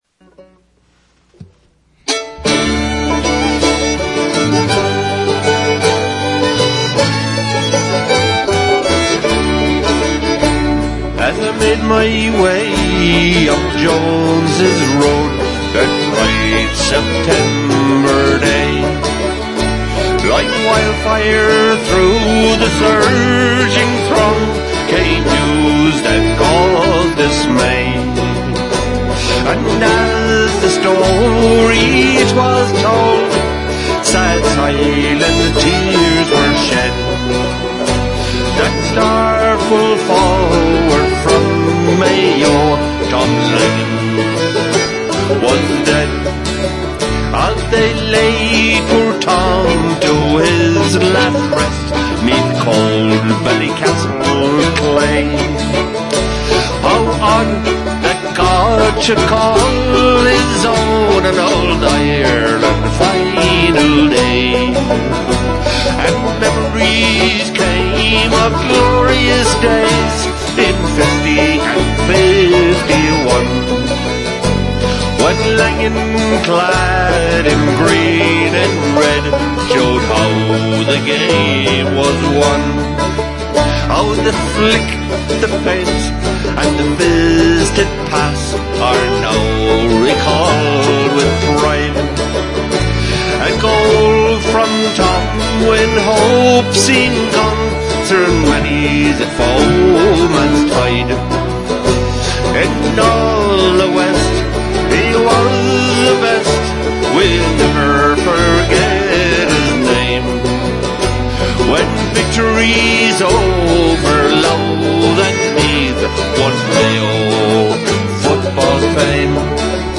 Melody:  Homes of Donegal